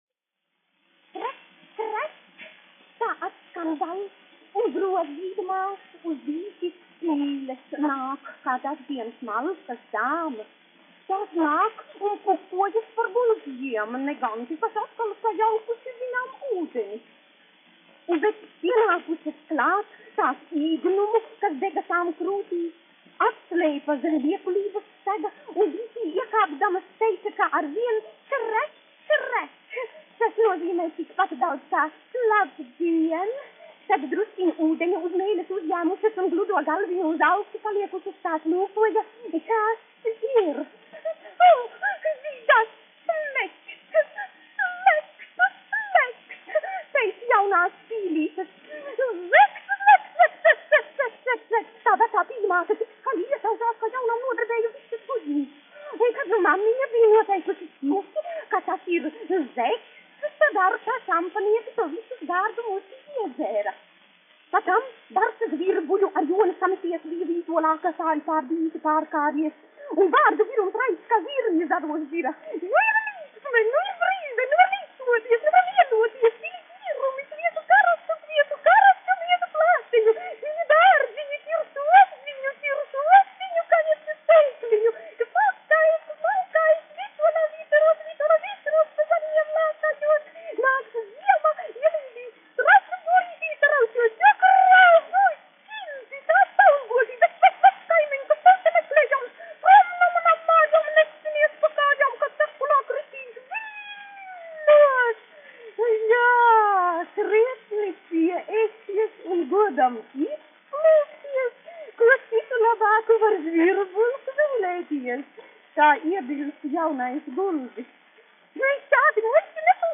1 skpl. : analogs, 78 apgr/min, mono ; 25 cm
Latviešu dzeja
Skaņuplate
Latvijas vēsturiskie šellaka skaņuplašu ieraksti (Kolekcija)